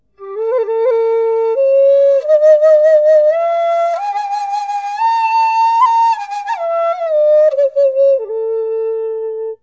iêu là nhạc khí thổi dọc trung âm, không đáy của Dân tộc Việt và một số Dân tộc Mường (Ống ối), Thái (Píthiu), Êđê (Ðinh klia), Vân Kiều (Cơlui).
àu âm của Tiêu trầm ấm, du dương, trữ tình phù hợp với tình cảm sâu lắng, êm dịu. Tiếng Tiêu nghe gần tưởng như nhỏ, nhưng thật ra vang rất xa.